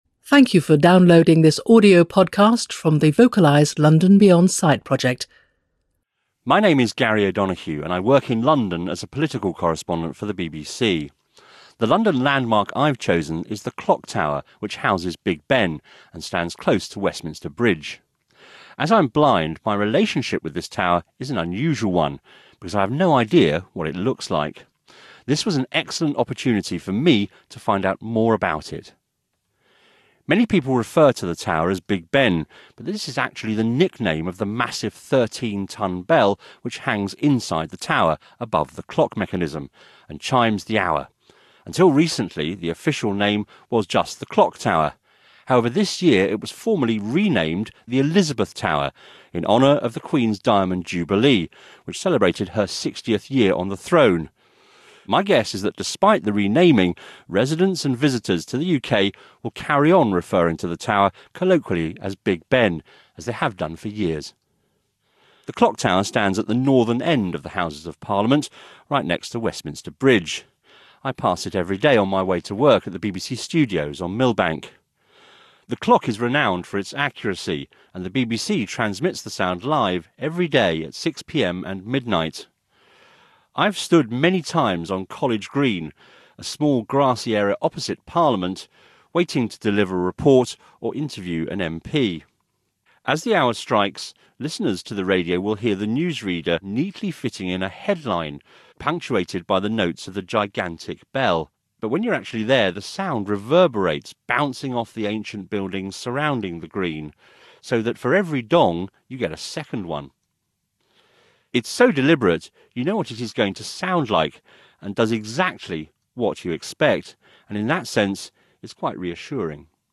타워 오디오 설명